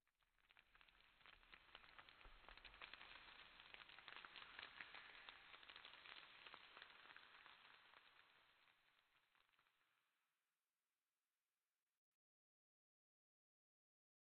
Minecraft Version Minecraft Version latest Latest Release | Latest Snapshot latest / assets / minecraft / sounds / ambient / nether / basalt_deltas / long_debris2.ogg Compare With Compare With Latest Release | Latest Snapshot
long_debris2.ogg